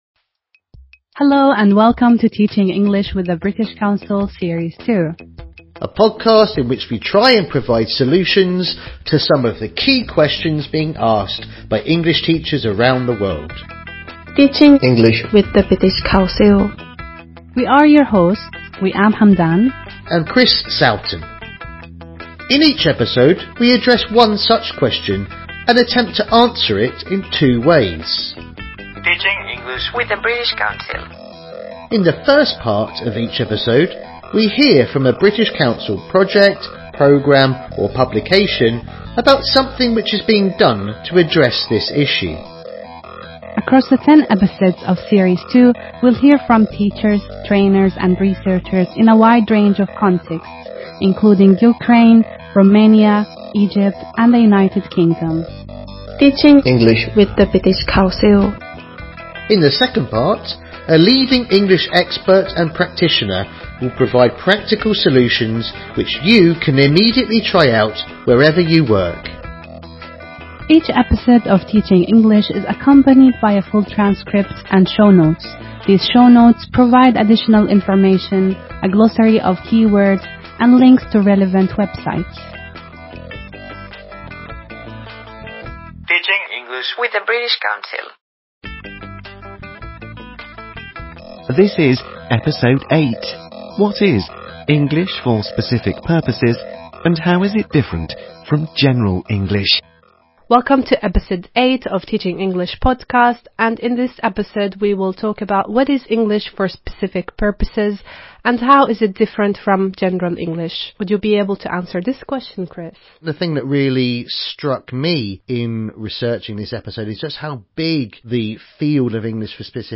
British Council: TeachingEnglish - series 2 overview In our second series of this ten-part podcast series from the British Council, we try and provide solutions to some of the key questions being asked by English teachers around the world. Each episode explores a specific topic through interviews, a focus on recent developments and reports on British Council initiatives in English language teaching.